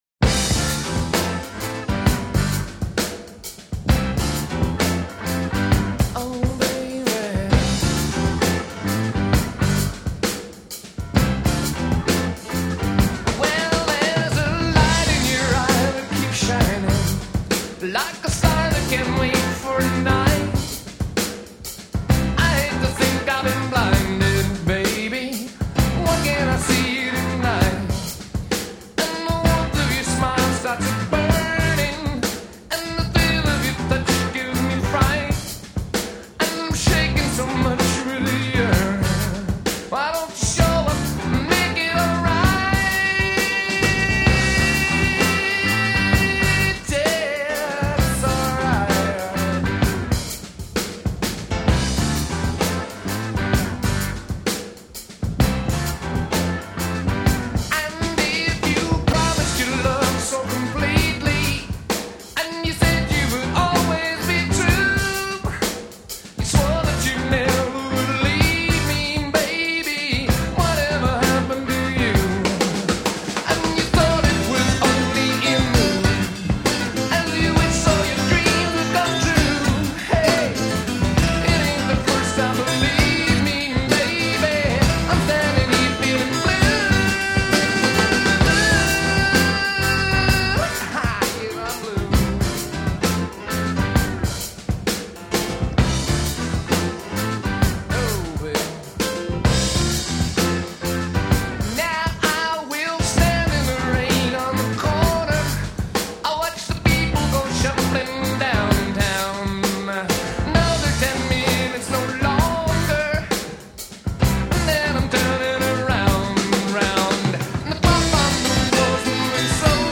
Only Drums Outtake